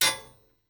metal_02.ogg